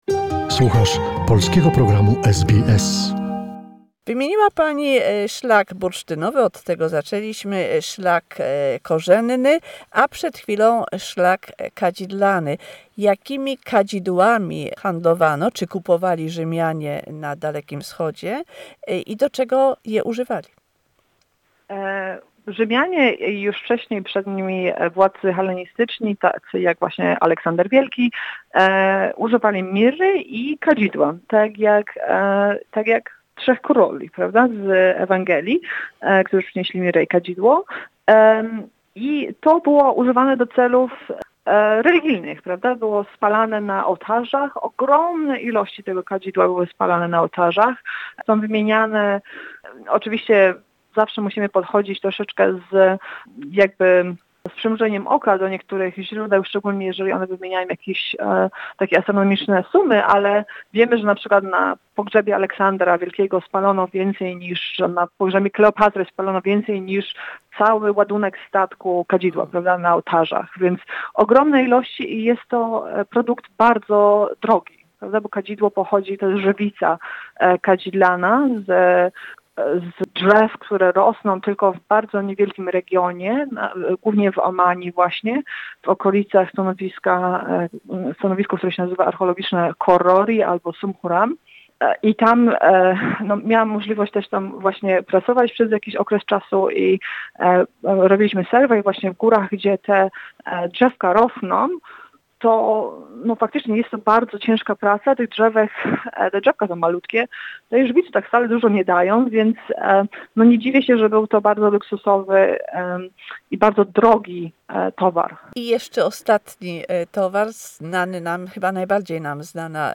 The fourth and last conversation with the historian and archaeologist